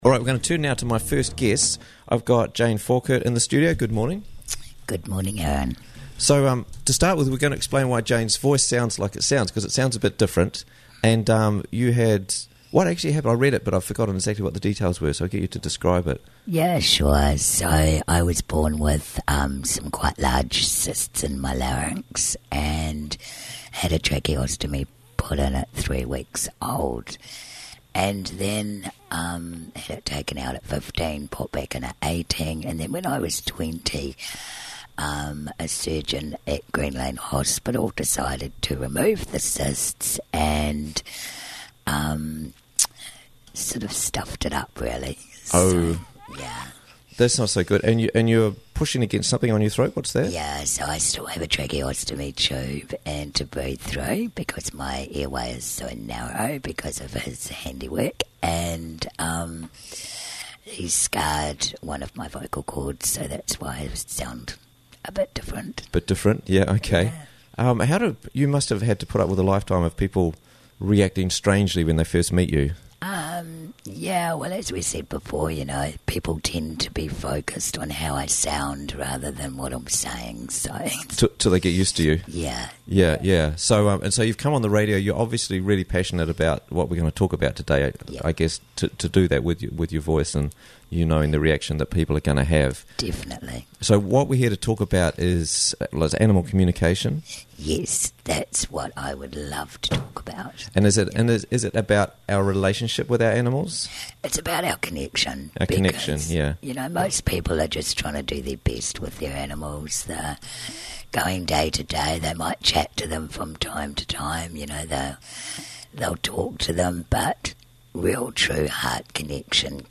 Quickly and easily listen to Interviews from the Raglan Morning Show for free!